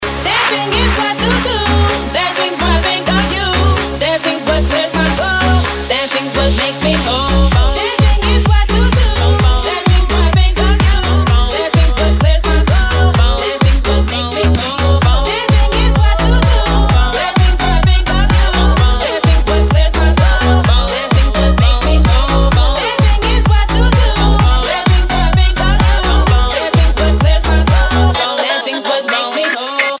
• Качество: 192, Stereo
Очередной ремикс старой известной песни от Русского Ди-джея